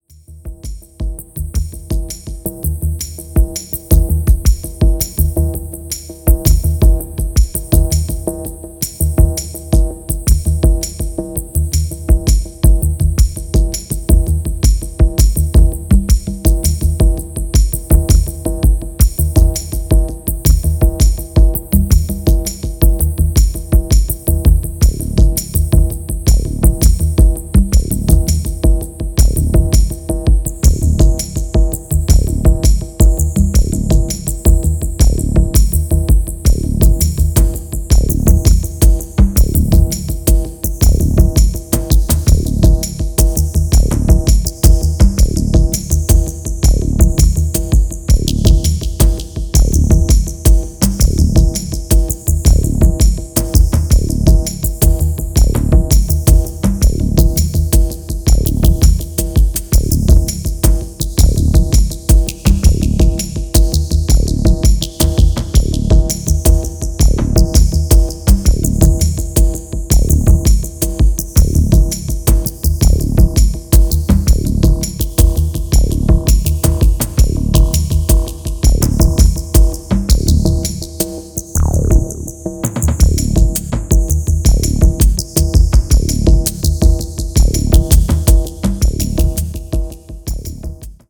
ミニマル&トライバルな82/164ハーフタイム